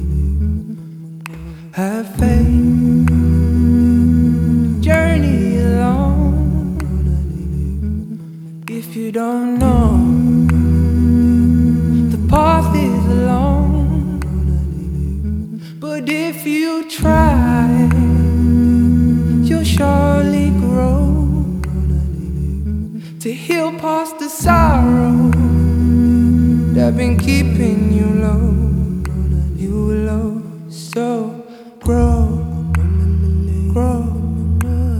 # Religious